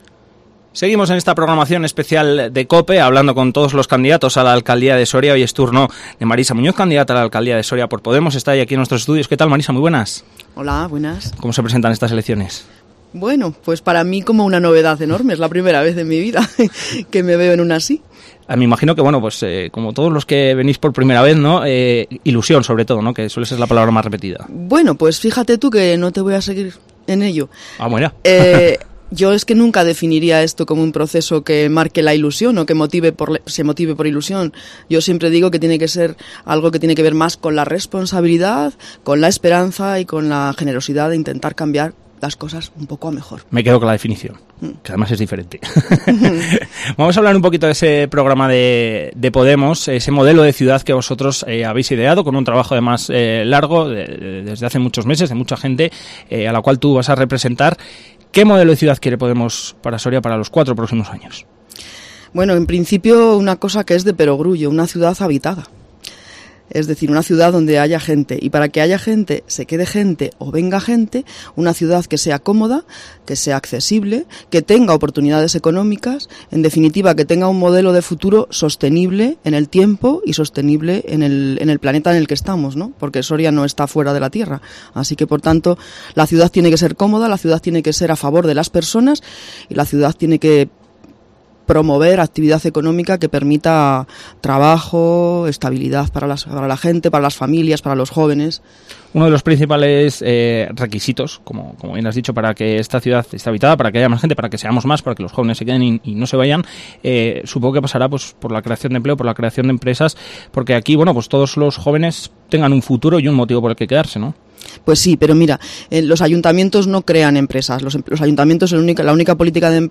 Entrevista PODEMOS elecciones municipales Soria